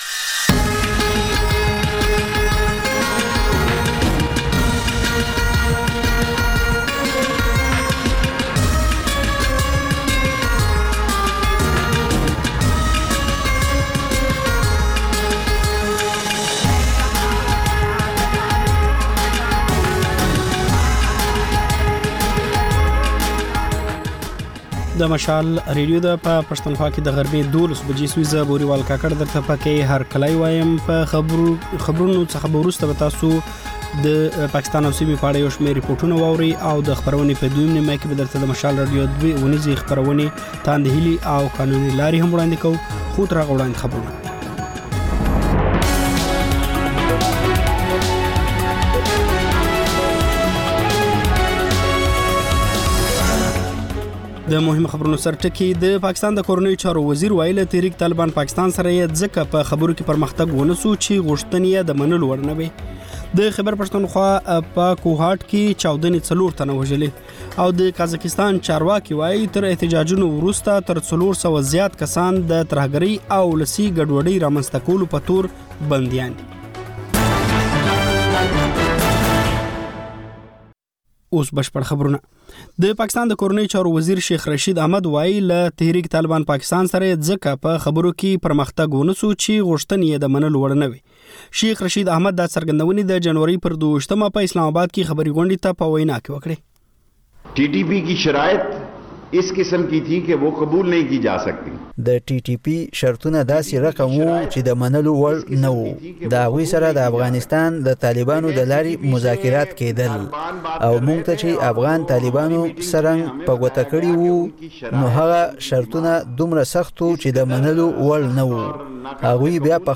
د مشال راډیو د نهه ساعته خپرونو لومړۍ خبري ګړۍ. په دې خپرونه کې تر خبرونو وروسته بېلا بېل سیمه ییز او نړیوال رپورټونه، شننې، مرکې، رسنیو ته کتنې، کلتوري او ټولنیز رپورټونه خپرېږي.